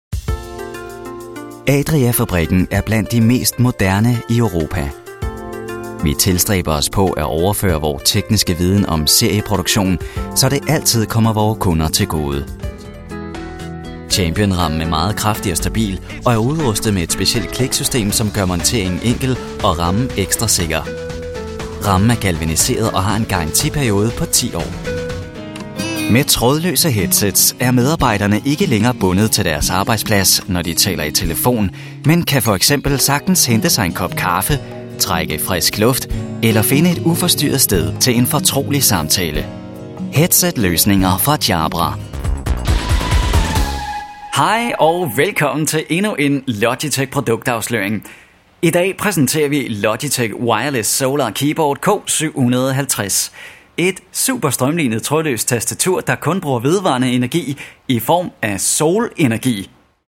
I specialize in the natural delivery, having a friendly and likeable voice the audience will feel comfortable with.
Sprechprobe: Industrie (Muttersprache):